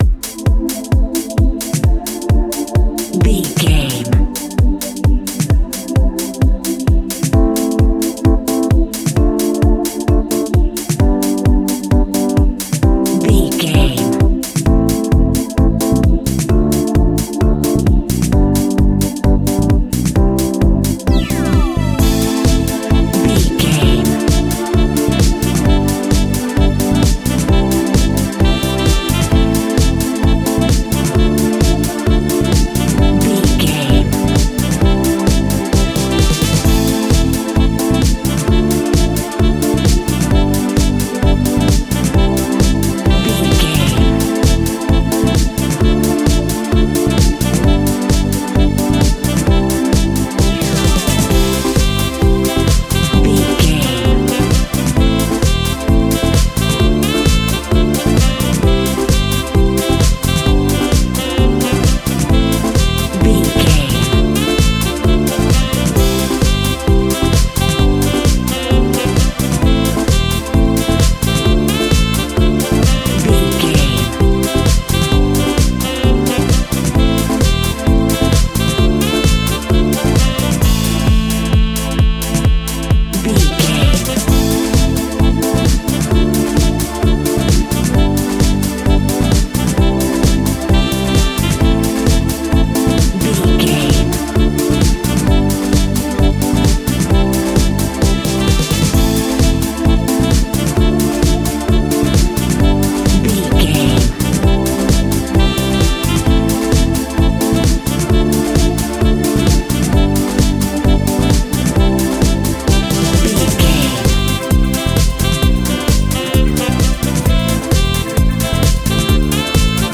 Aeolian/Minor
energetic
funky
electric piano
bass guitar
drums
saxophone
funky house
groovy
upbeat